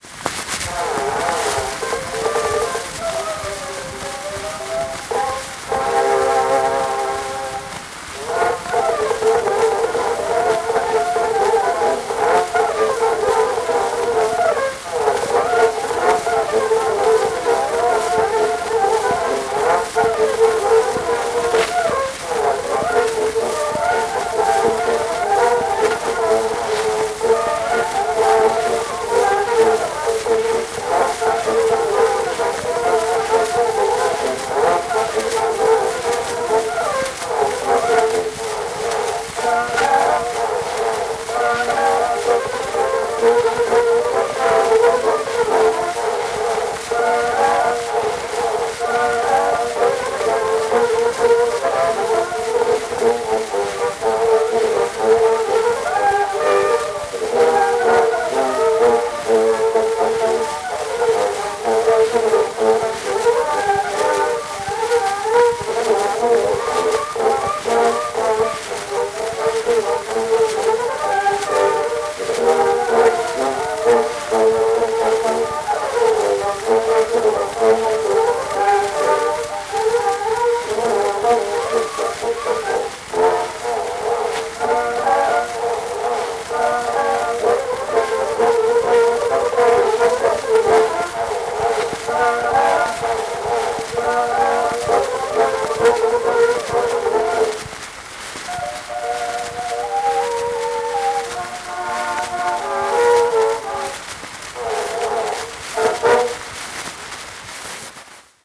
Sousa Band performing "Orange blossoms"